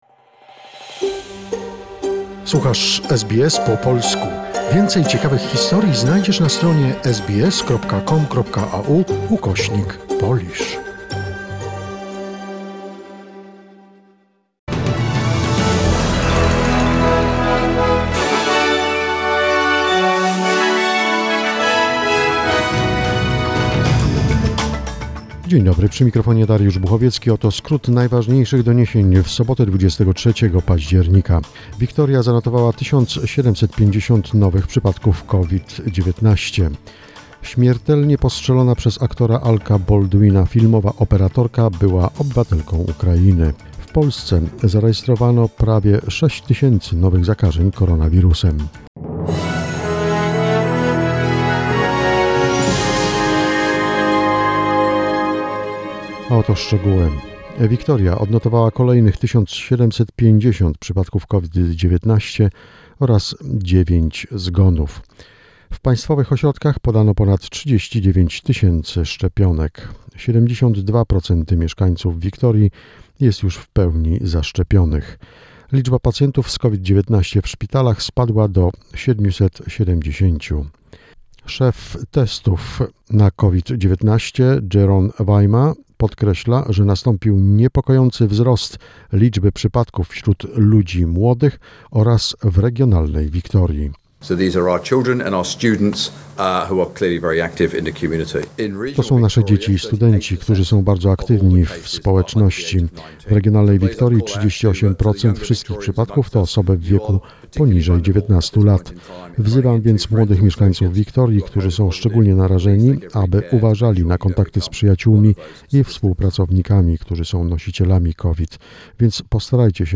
SBS News Flash in Polish, 23 October 2021